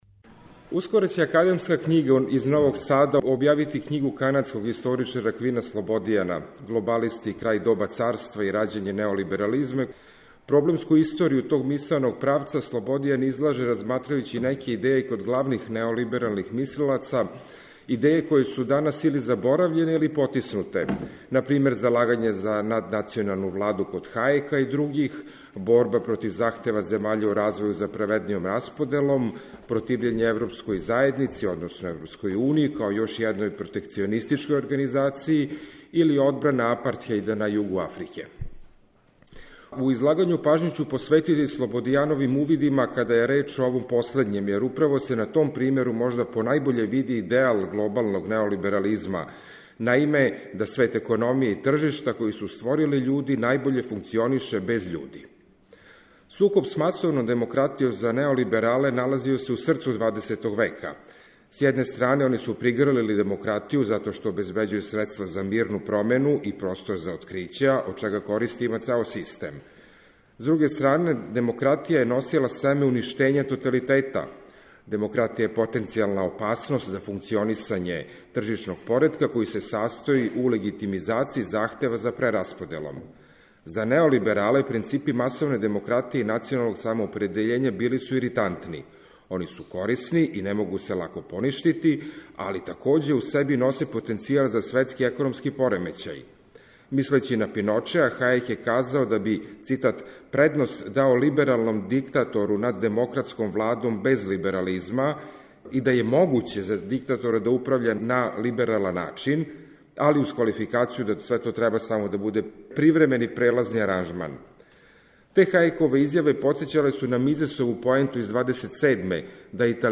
Преносимо излагања са научних конференција и трибина.